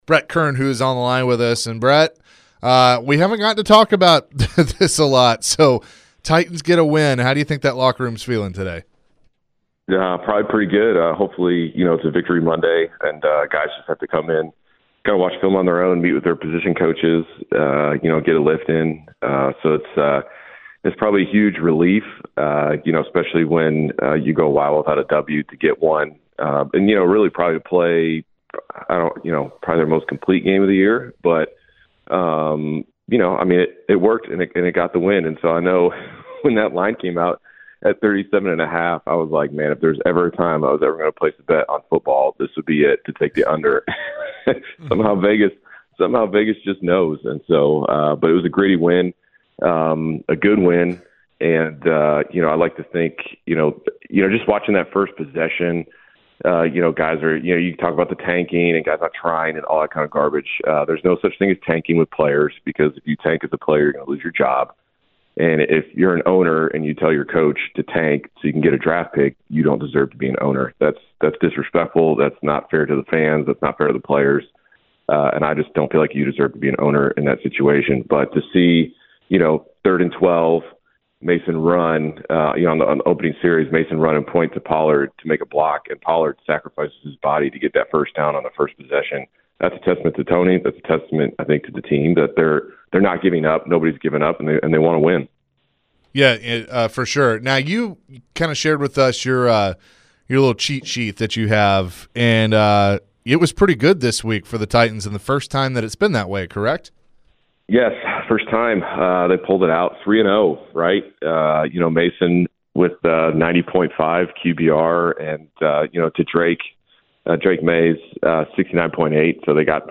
Former Titans Punter Brett Kern joined the show and shared his thoughts on the Titans overtime win over the Patriots. Brett shared his thoughts on what it's like to win a game on a team that has a losing record.